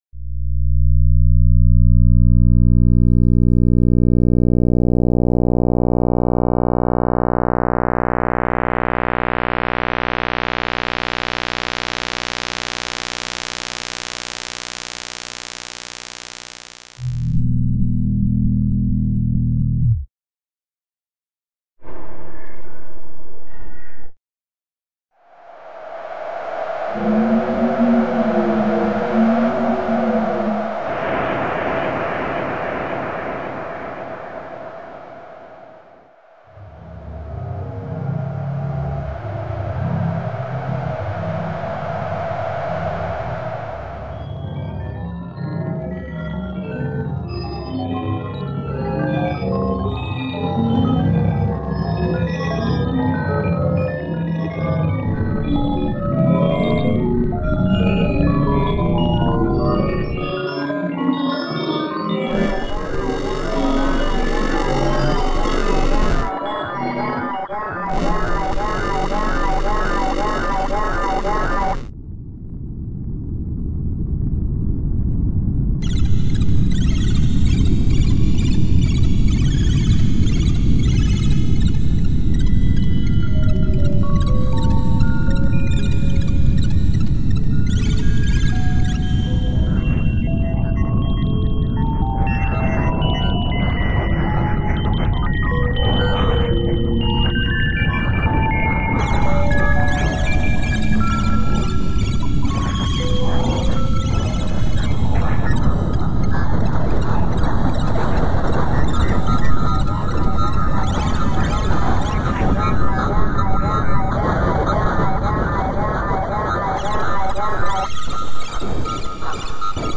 nhạc hòa tấu
tác giả đã tự tạo ra những âm thanh đó.